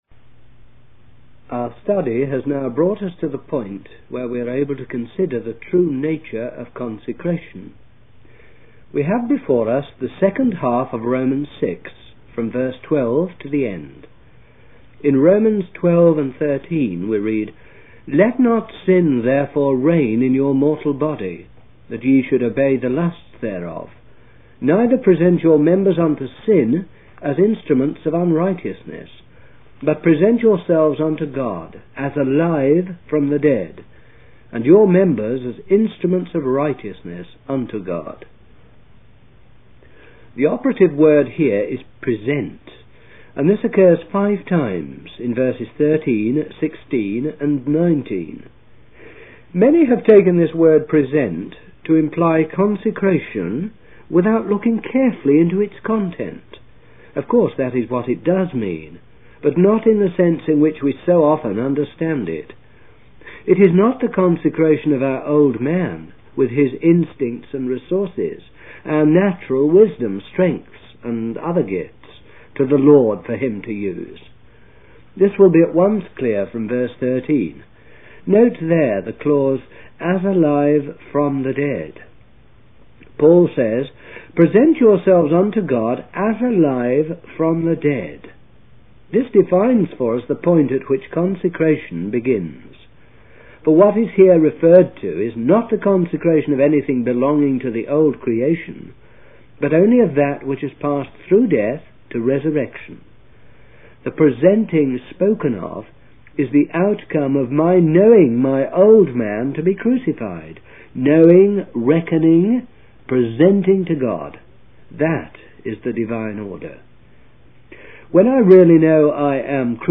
In this sermon, the speaker emphasizes the importance of true commitment to God. He explains that when we offer ourselves to God, He takes it seriously and begins to break and transform us.